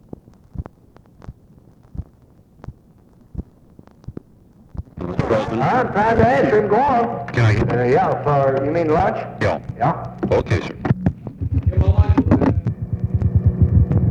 Conversation with GEORGE REEDY, July 15, 1964
Secret White House Tapes | Lyndon B. Johnson Presidency